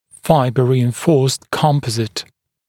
[‘faɪbə ˌriːɪn’fɔːst ‘kɔmpəzɪt][‘файбэ ˌри:ин’фо:ст ‘компэзит]композитный материал, усиленный волокнами